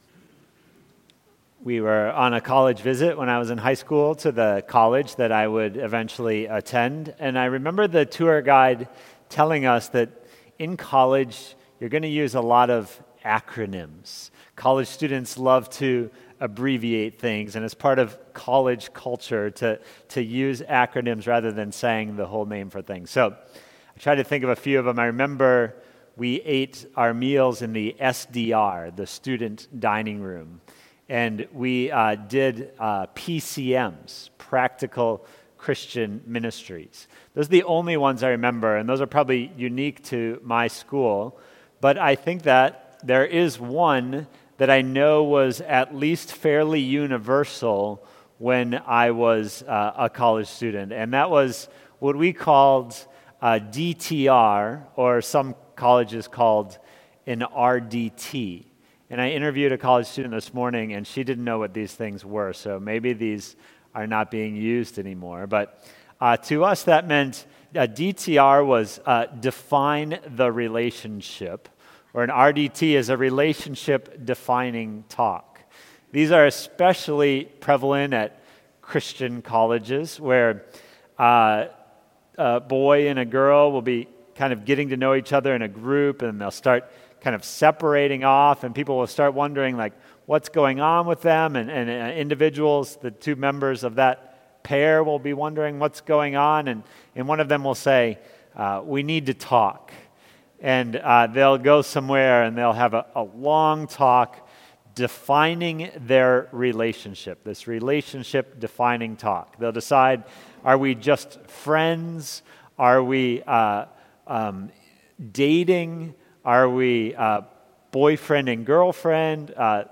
Non-Series Sermons Passage: Matthew 28:16-20 Matthew 28:16-20.